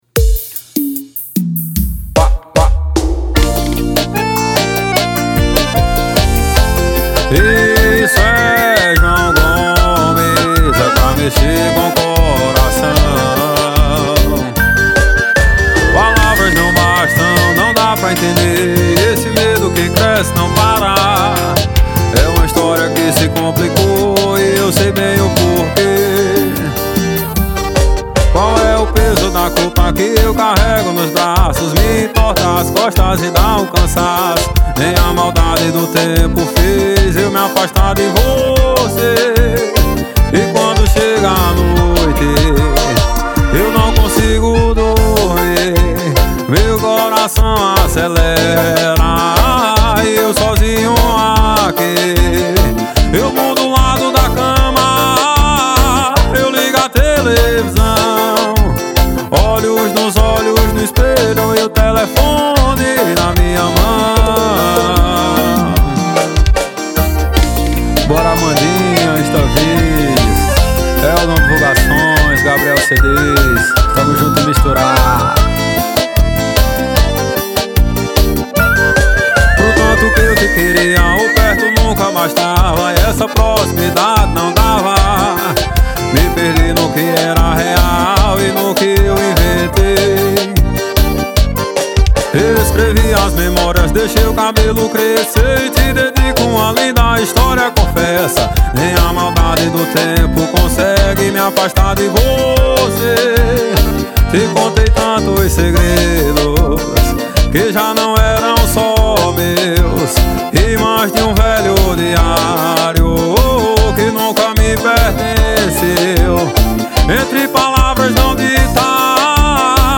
2024-02-14 17:57:24 Gênero: Forró Views